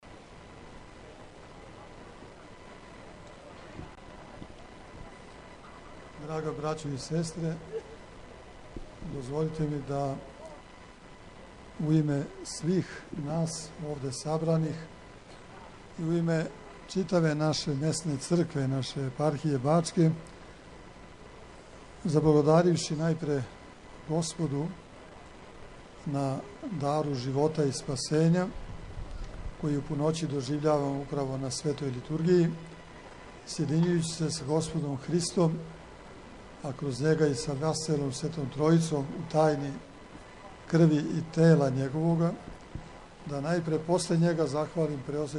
Овогодишњи литургијски спомен чуда које је Свети Архистратиг учинио у граду Хони (Колоси) одржано је у Сомбору, на Тргу Светог Георгија, код Светогеоргијевске цркве.
Беседа Епископа Иринеја: